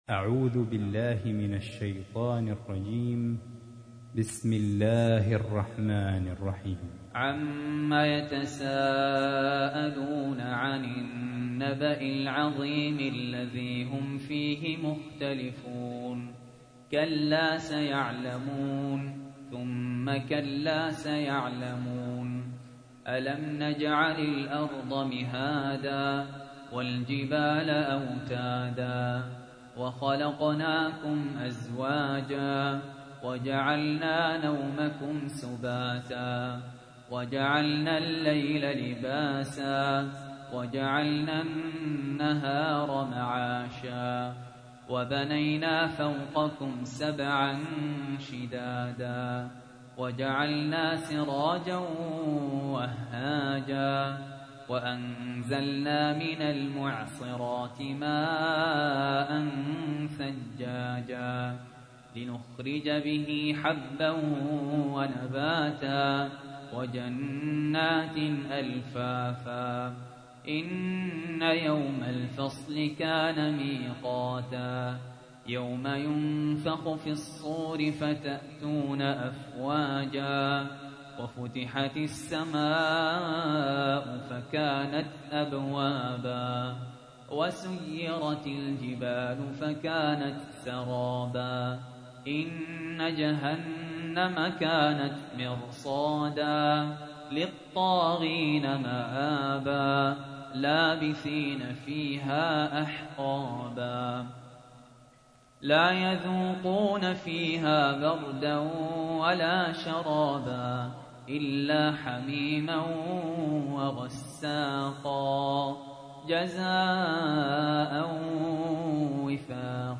تحميل : 78. سورة النبأ / القارئ سهل ياسين / القرآن الكريم / موقع يا حسين